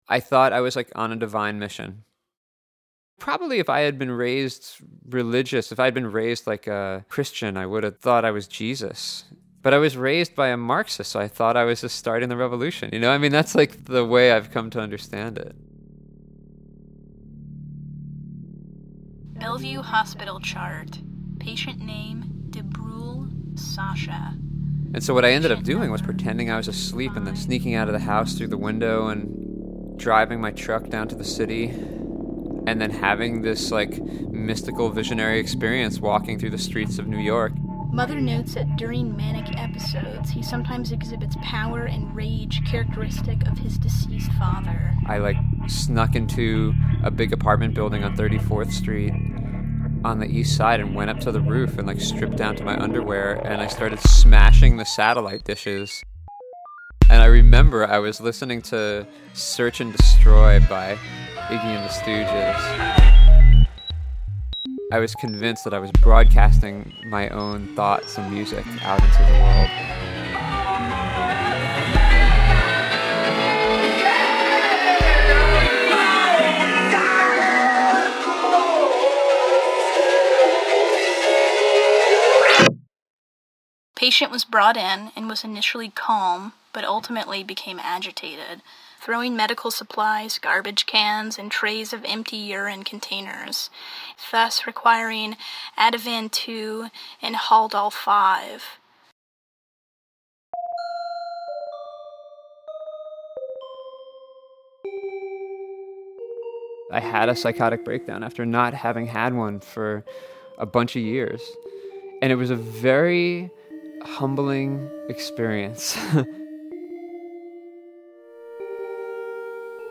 “Divine Mission” 3 Minute Audio Spoken Word On Madness – Maps to the Other Side
Hey this is a short audio piece that’s taken from an interview I did when I was on book tour. It’s a story about getting locked up in the psych ward for thinking I was on a divine mission.
Radio documentary.